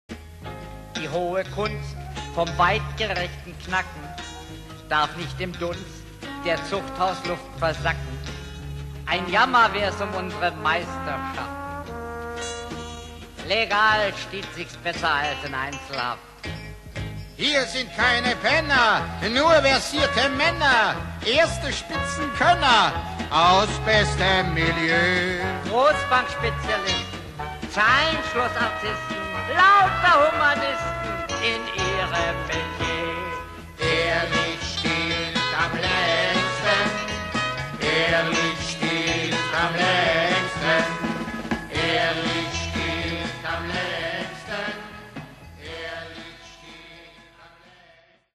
Musical für den Rundfunk (Originalhörspiel)
Besetzung: 9 Darsteller (Sprecher/Sänger)